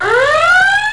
NUCLEAR.WAV